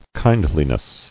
[kind·li·ness]